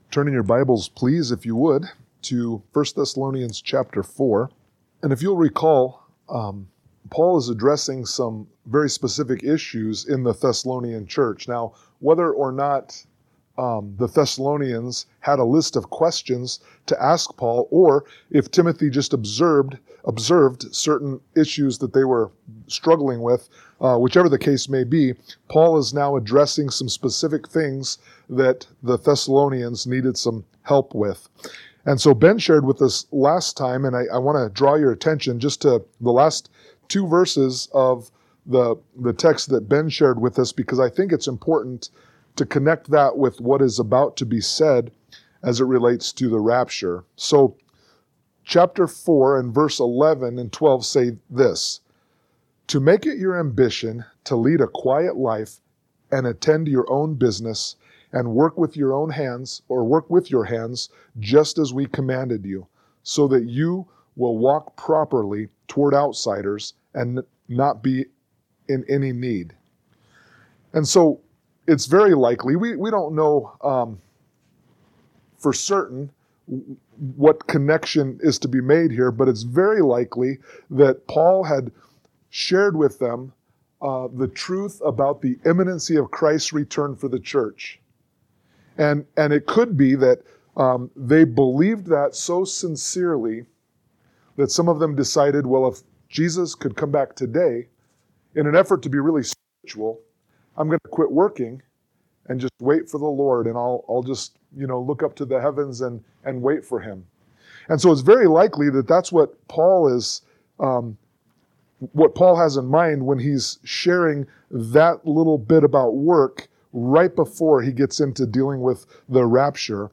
Sermon-4_6_25.mp3